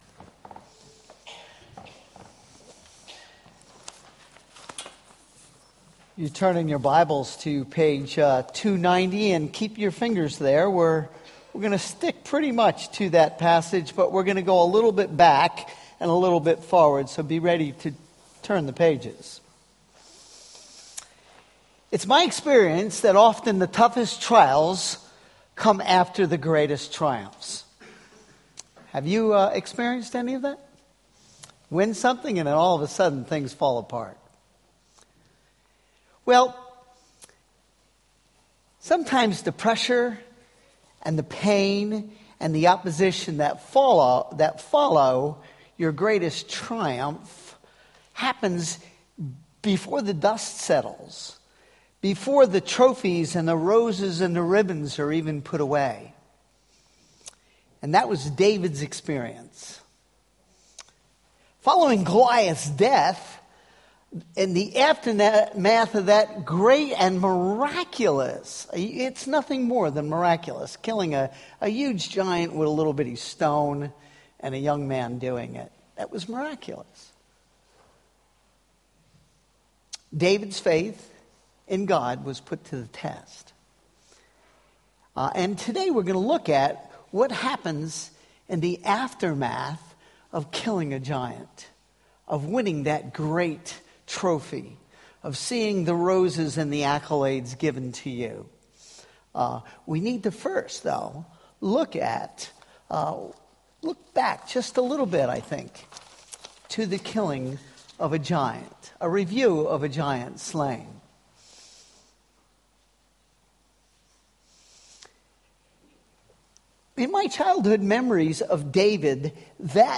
Worship Service
Sermon